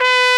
Index of /90_sSampleCDs/Roland L-CDX-03 Disk 2/BRS_Tpt mf menu/BRS_Tp mf menu